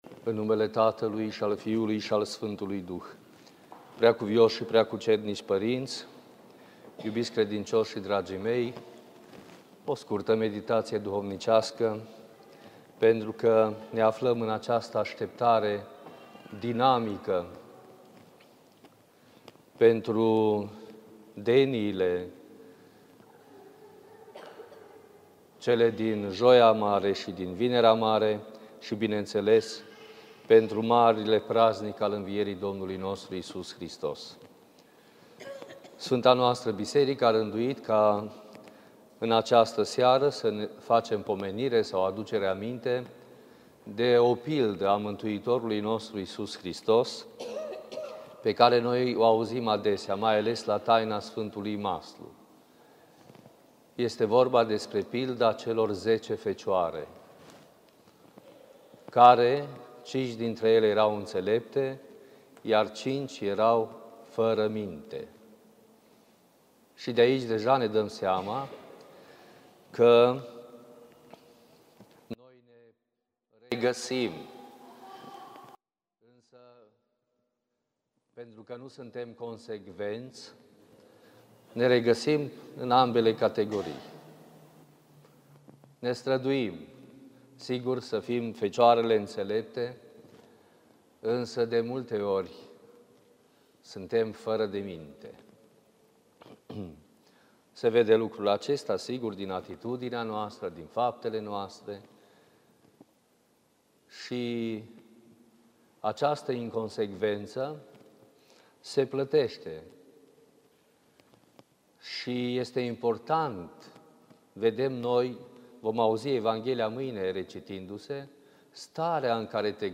Predică la Denia din Sfânta și Marea Marți